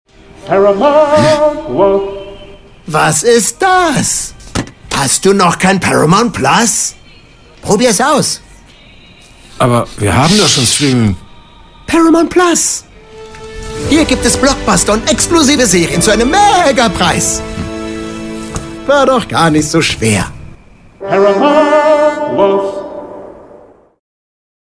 markant, sehr variabel
Mittel plus (35-65)
Commercial (Werbung)